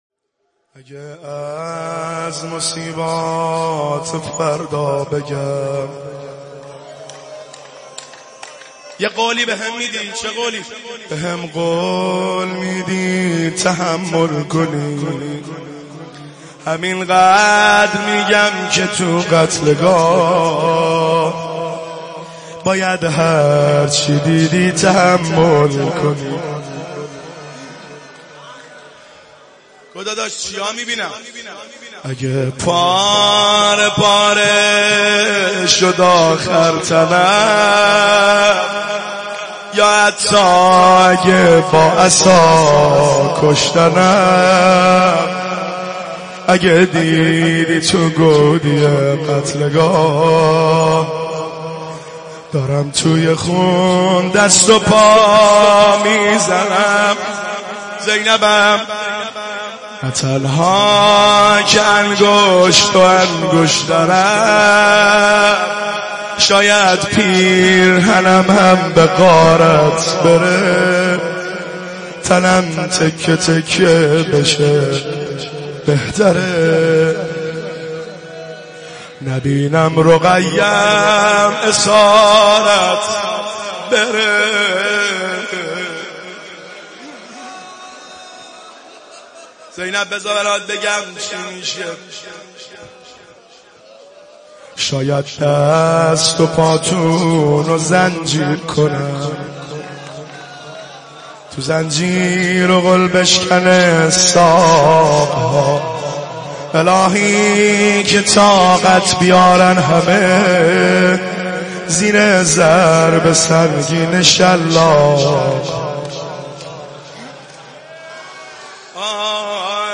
روضه شب عاشورا